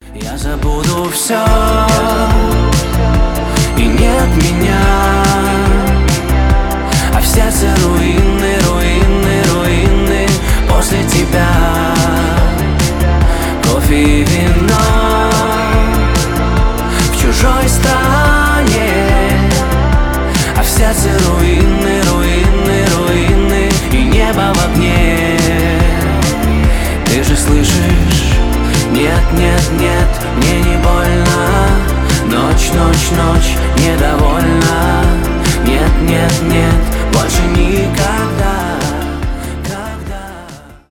поп , приятный голос